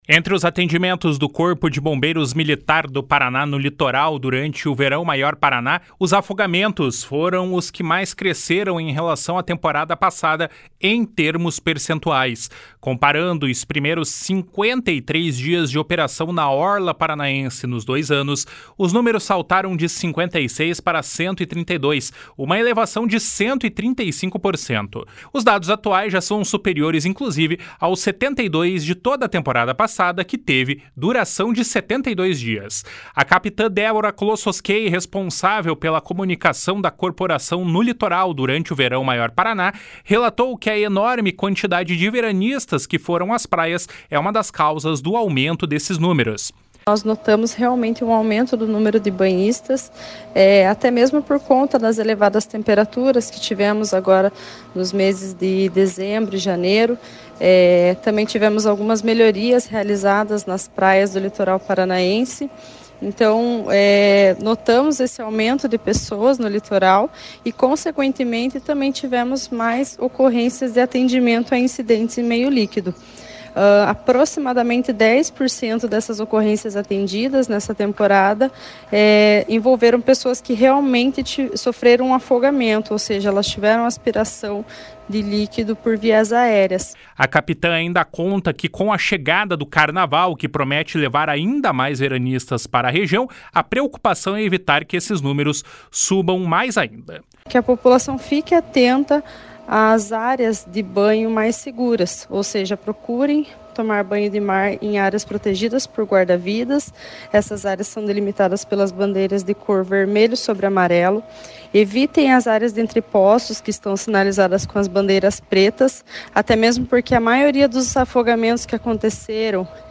BOMBEIROS JA SALVARAM 1,4 MIL PESSOAS NO LITORAL.mp3